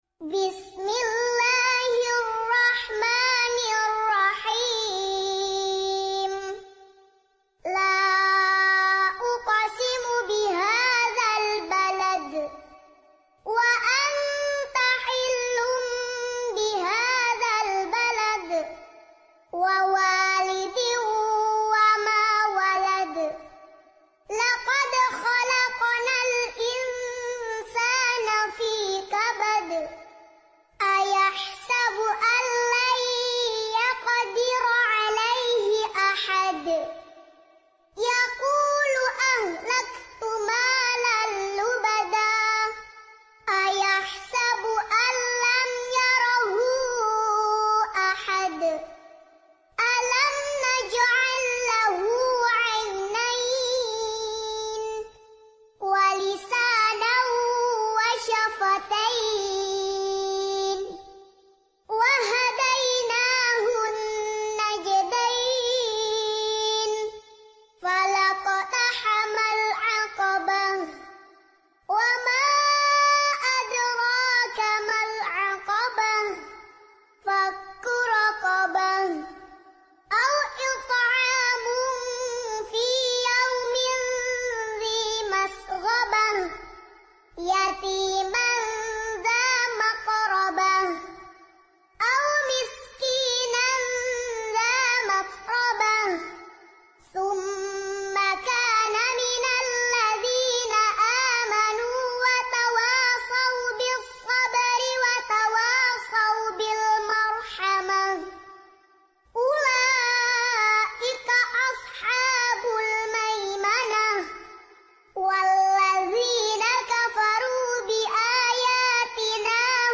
Ngaji Juz Amma Metode Ummi Murottal Anak